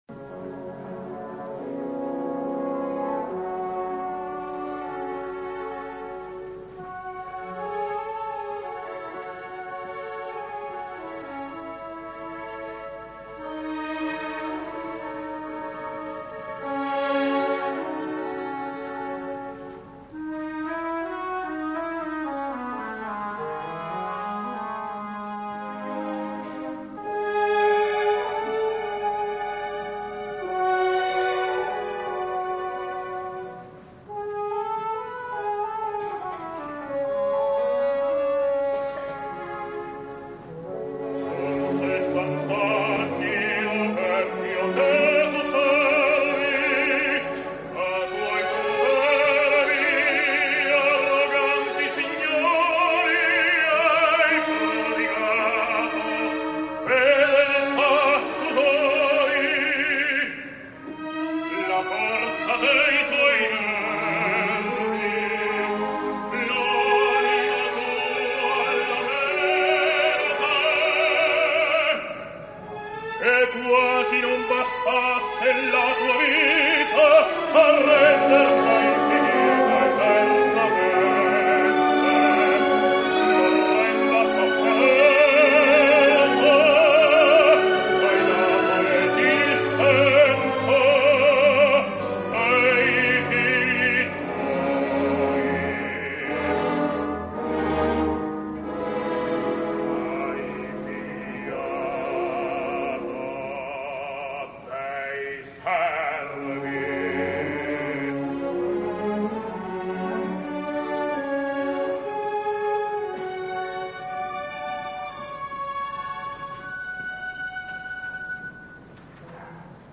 {historischer Verismo}
Dir.: B.Bartoletti - Chicago Op Orch
Carlo Gérard [Bariton]